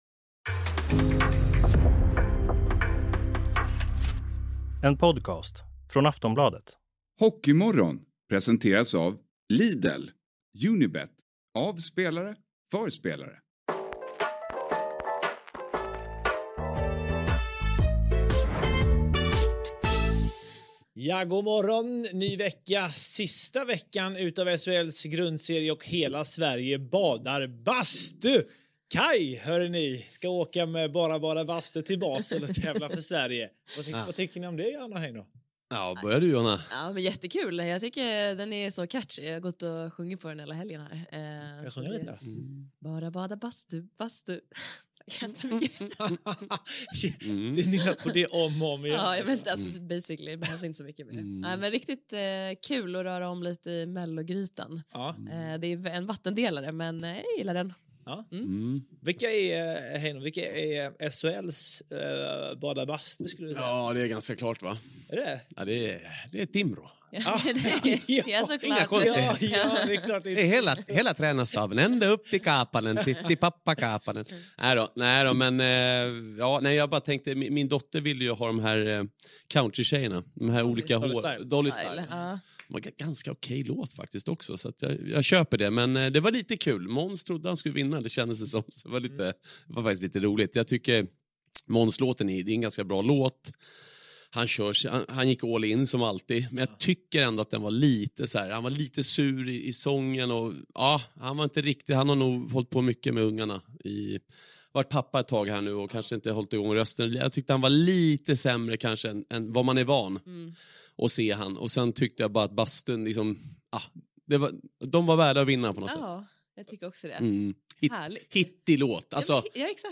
I studion: